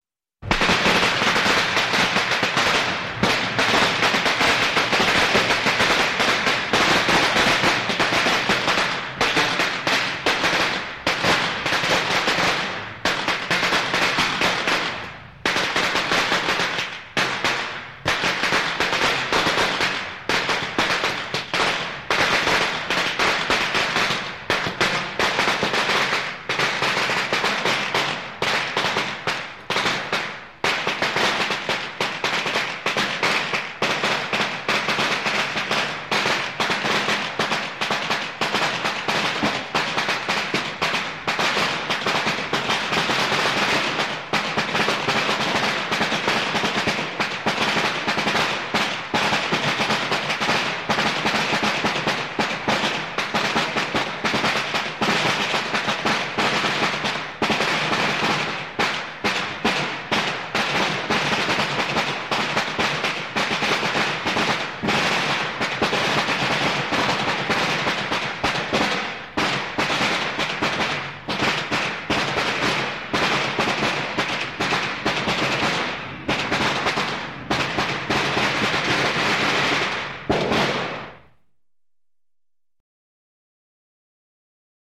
SFX鞭炮声(响亮爆竹声)音效下载
SFX音效